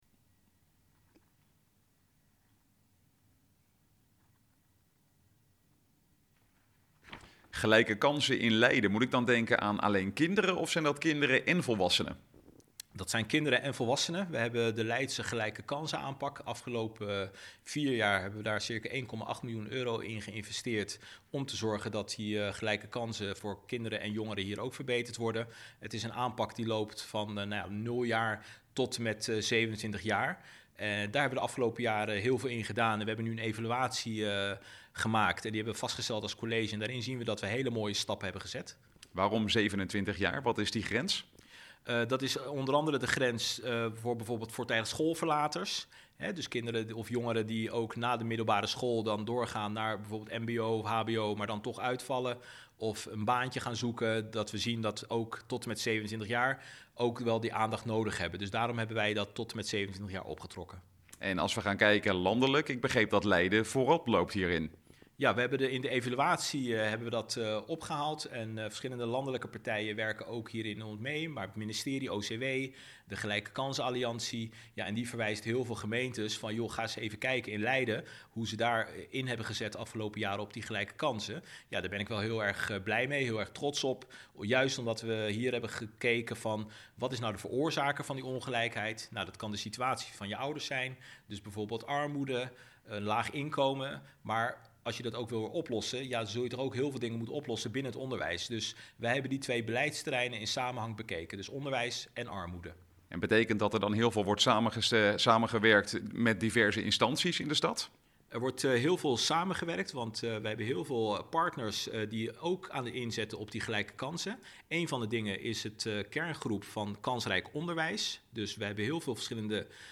Wethouder Jermoumi in de radiostudio van Centraal+.
Wethouder Abdelhaq Jermoumi over gelijke kansen